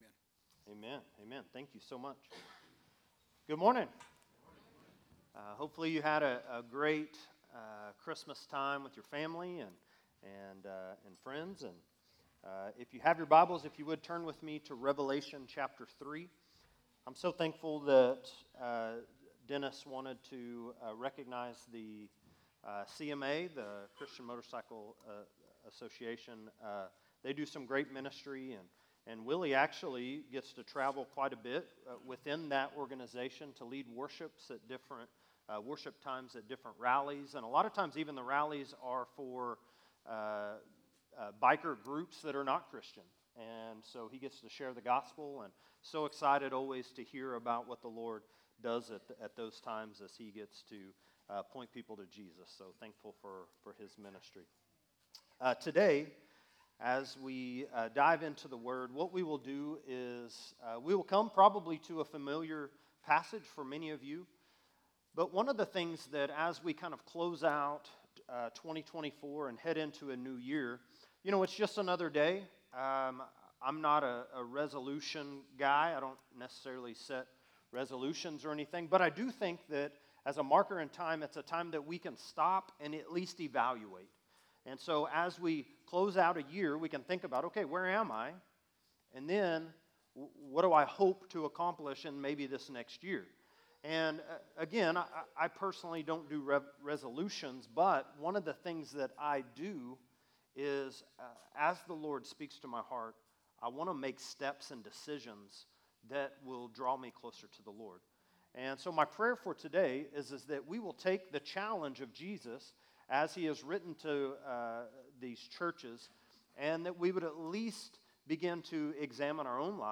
A message from the series "Skiatook FBC." Jesus Reigns - Revelation 5:1-14 [CCLI #: 58367]